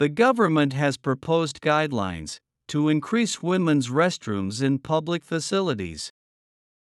１）スロー（前半／後半の小休止あり）